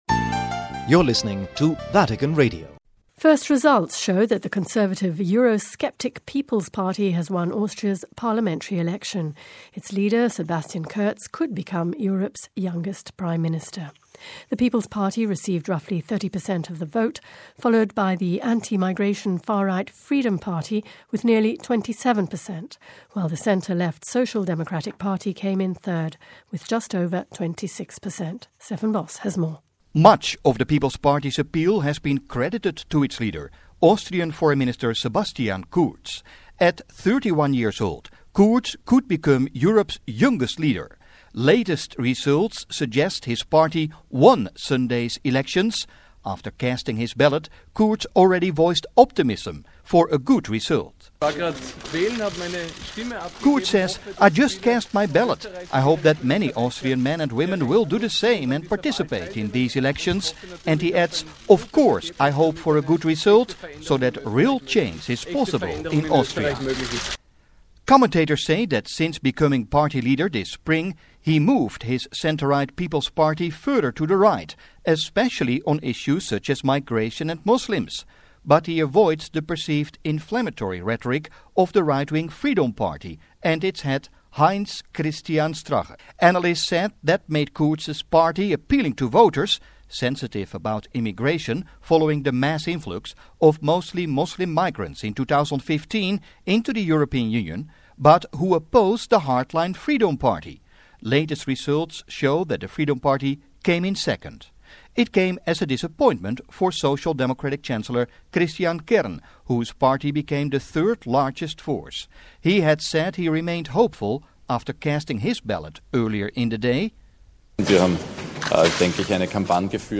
There were shouts of joy at campaign headquarters as latest results show that the People Party received most votes.